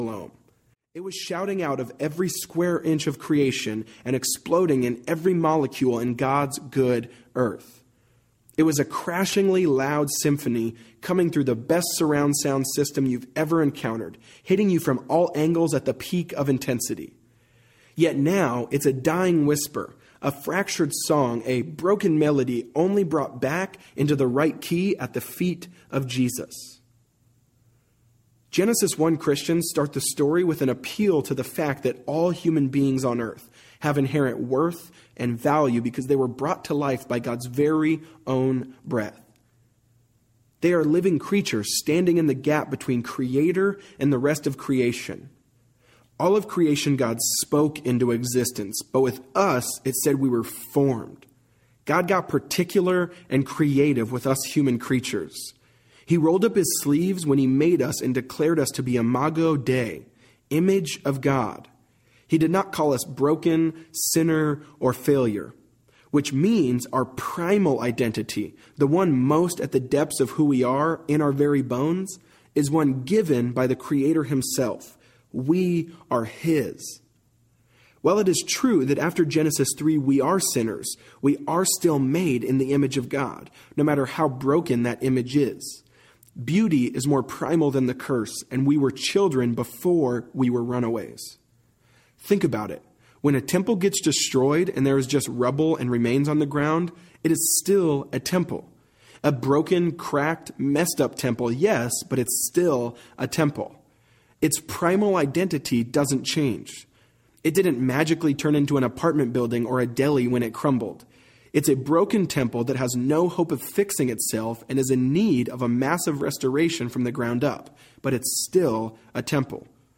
It’s Not What You Think Audiobook
Narrator
Jefferson Bethke